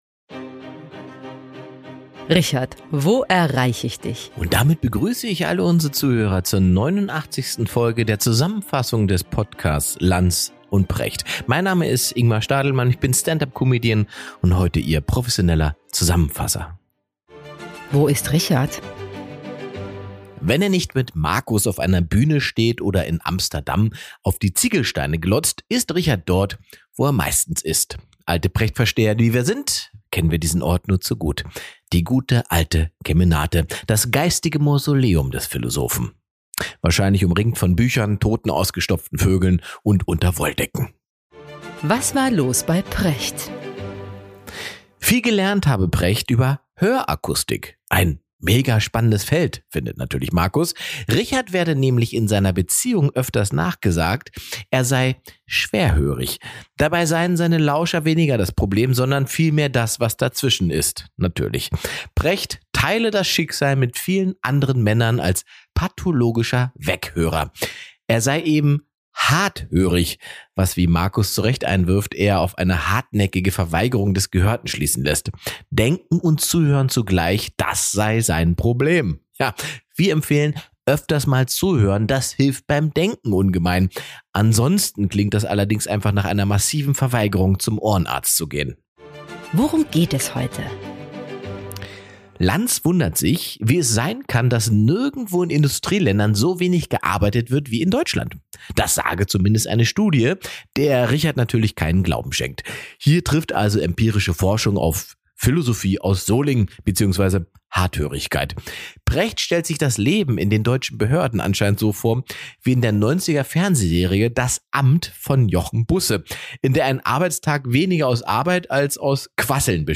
In dieser Podcast-Episode fasst Ingmar Stadelmann die neunundachtzigste Ausgabe von Lanz & Precht zusammen. Die Rubriken werden von der wunderbaren Sprecherin Franziska Weisz vorgelesen.